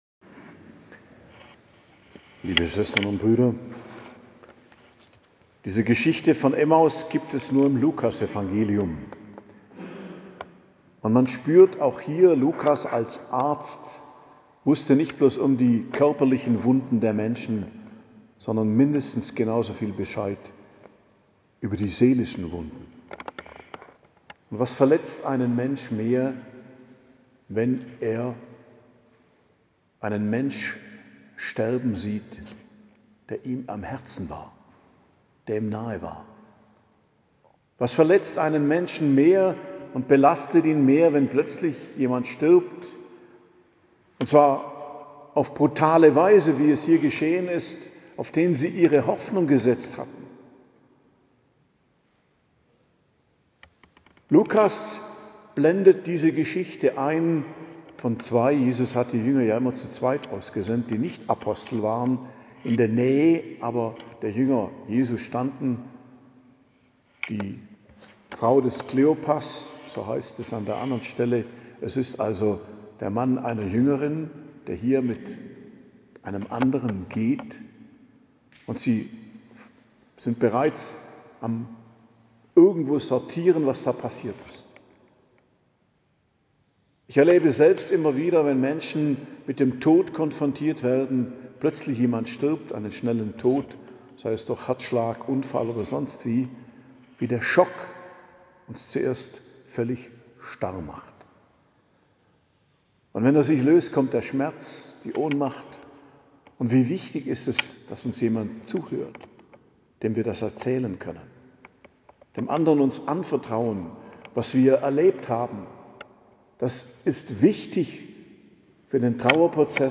Predigt am Ostermontag, 21.04.2025 ~ Geistliches Zentrum Kloster Heiligkreuztal Podcast